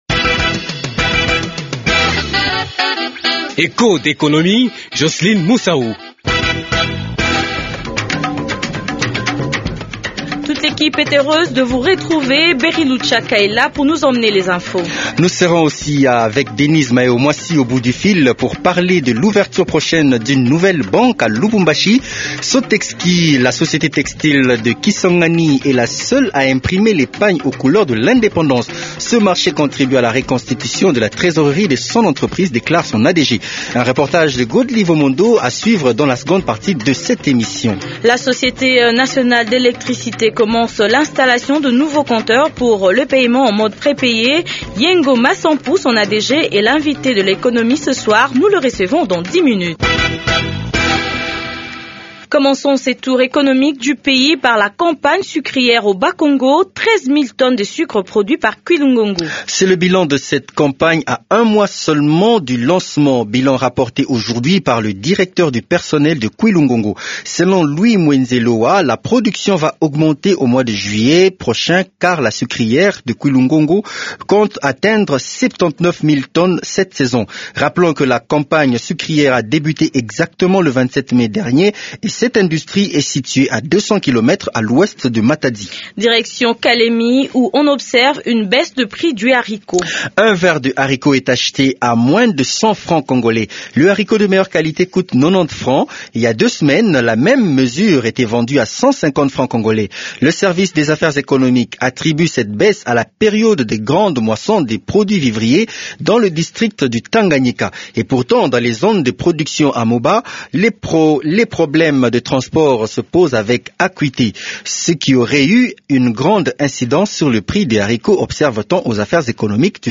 La société textile de Kisangani est la seule à fabriquer les pagnes aux couleurs de l’indépendance. Reportage à suivre dans Echos d’économie.